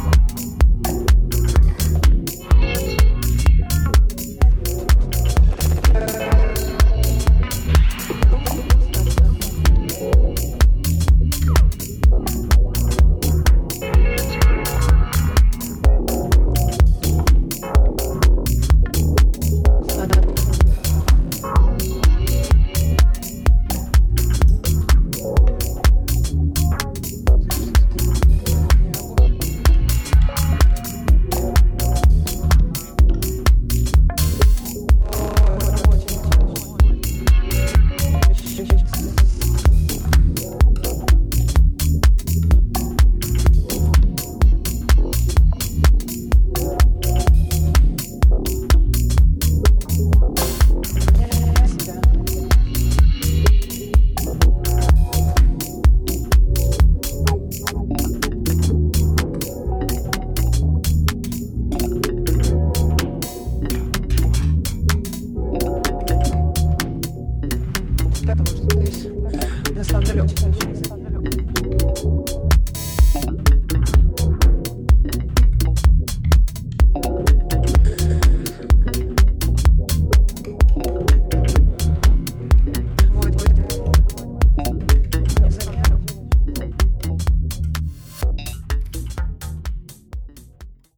トリッピーなウワモノでハメる、淡々としたミニマル・ハウスB面もナイスです！